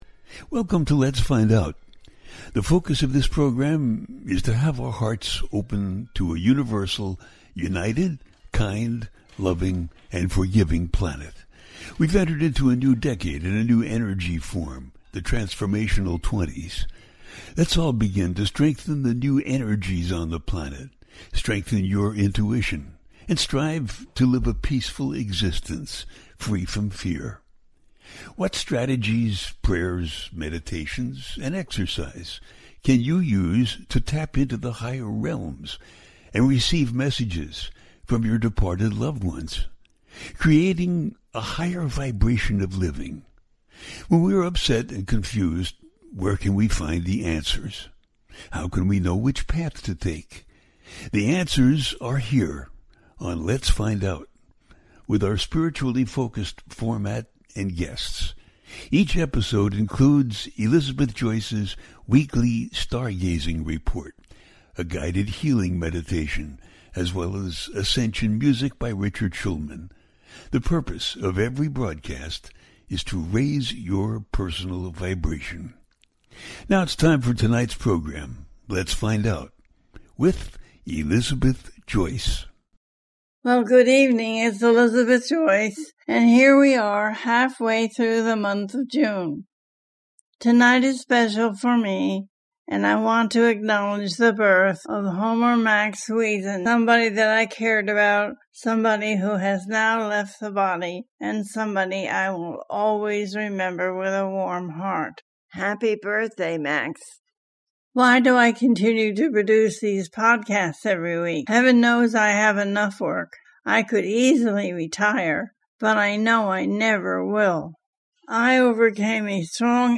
What is War For? Absolutely Nothing - A teaching show